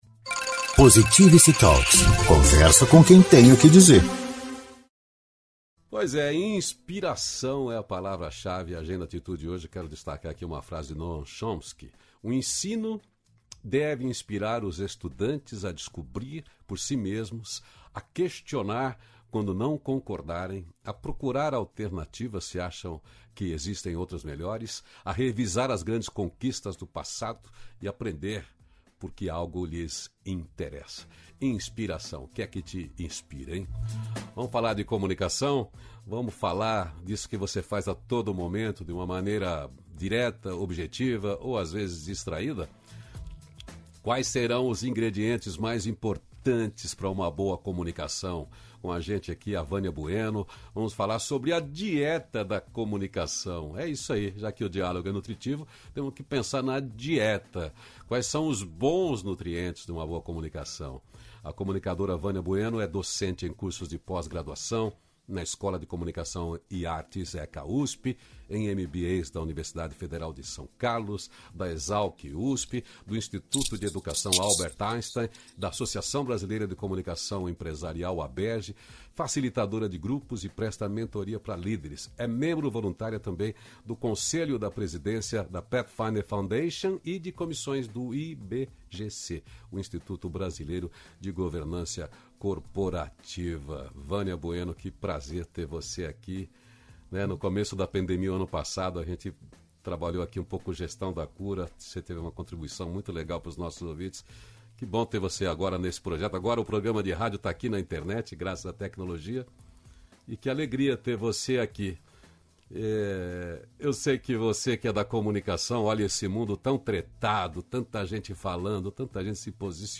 265-feliz-dia-novo-entrevista.mp3